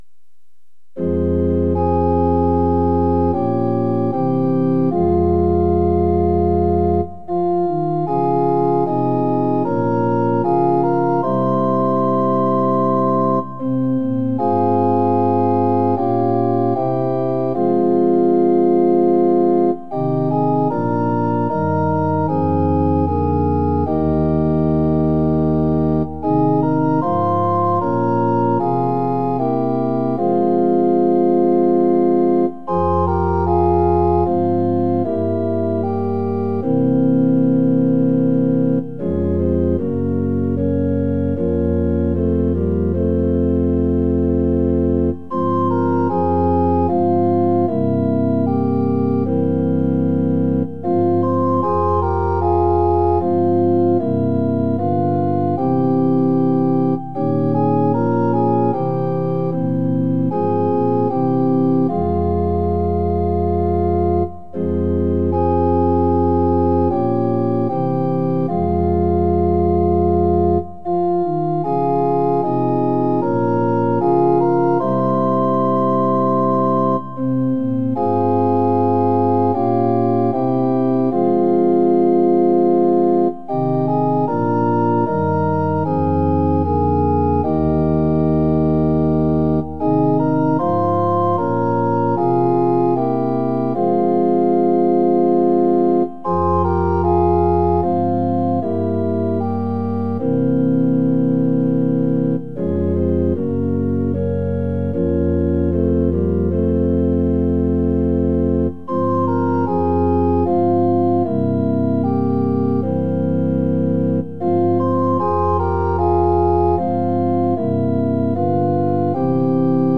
◆　４分の４拍子：　四拍目から始まります。